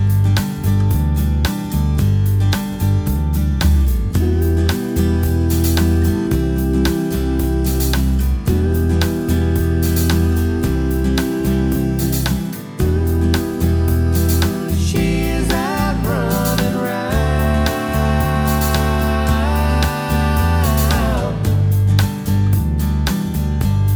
No Lead Guitar Soft Rock 2:53 Buy £1.50